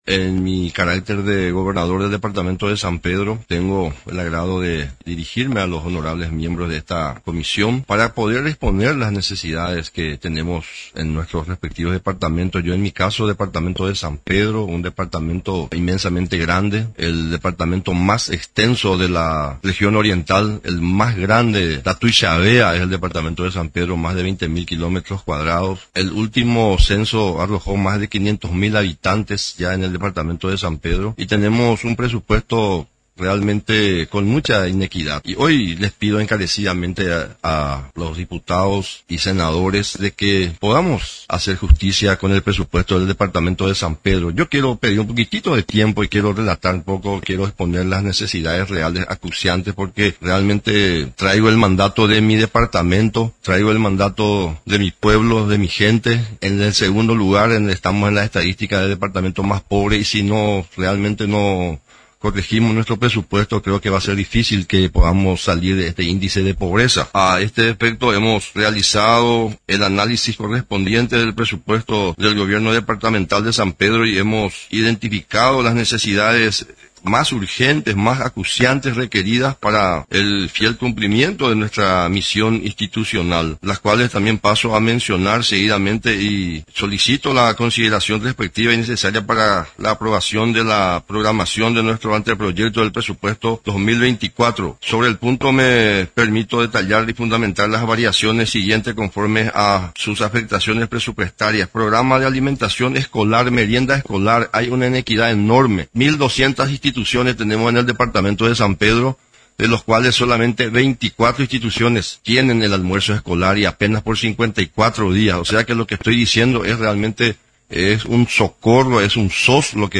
En la oportunidad, el gobernador de San Pedro, el más extenso de la región oriental, Freddy D’ecclesiis, durante su intervención, resaltó que el último censo realizado arrojó una población de unos 500 mil habitantes, quienes no gozan en plenitud de sus derechos como ciudadanos.